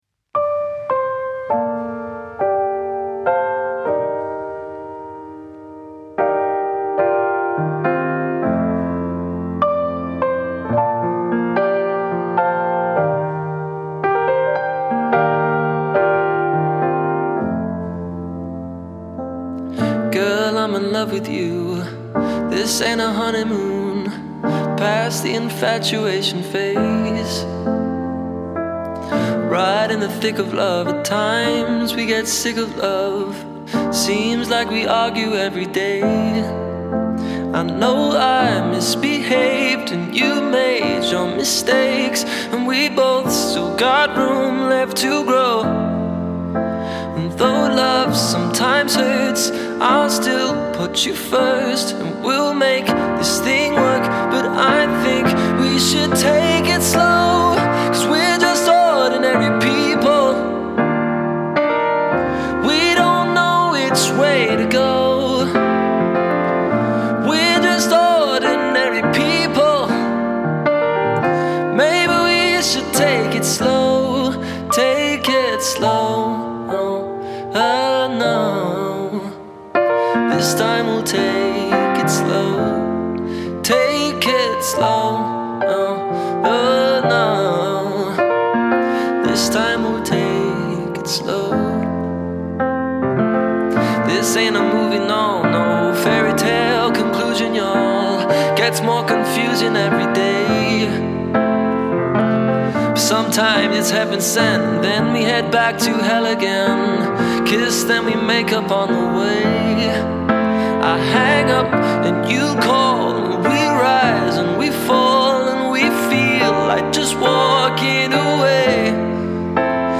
From £652 + travel | Solo Singer, Pianist and Guitarist